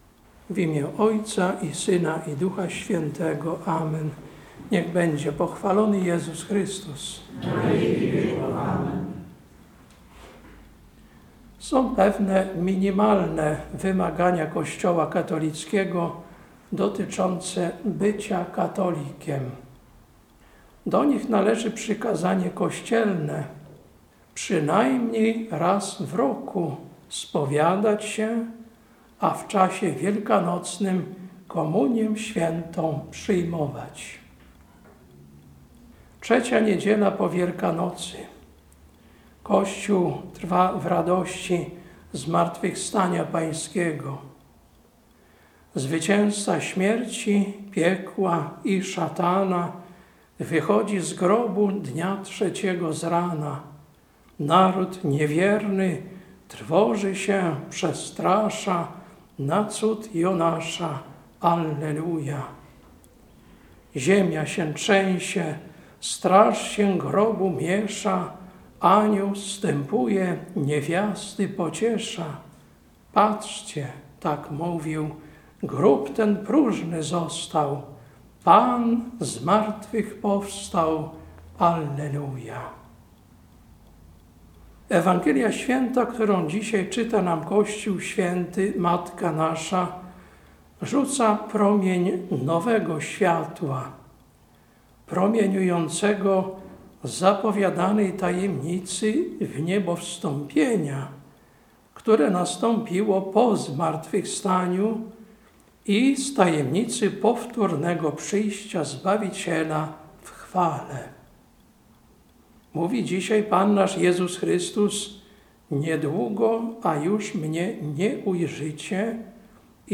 Kazanie na III Niedzielę po Wielkanocy, 21.04.2024 Lekcja: 1 P 2, 11-19 Ewangelia: J 16, 16-22